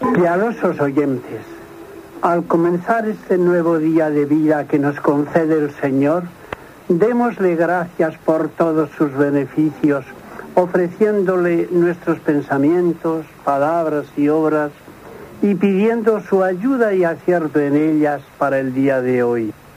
Introducció abans del res del Rosari